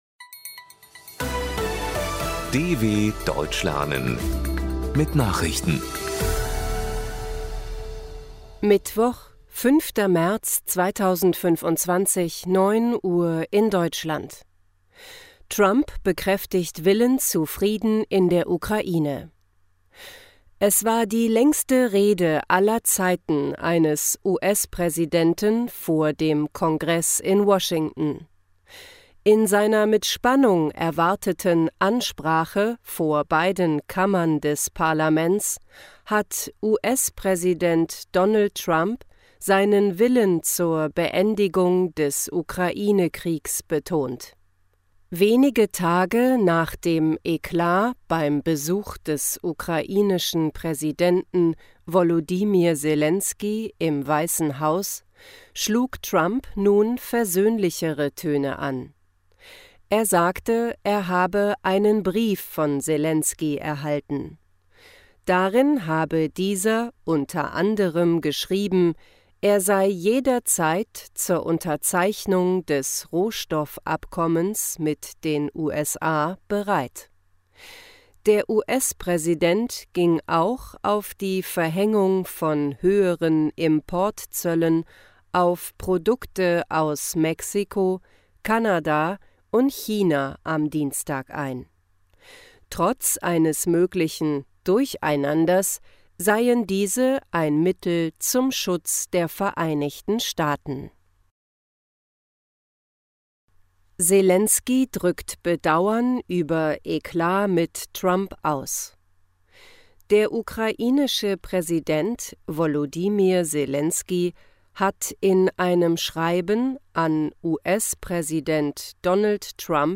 Das langsam und verständlich gesprochene Audio trainiert das Hörverstehen.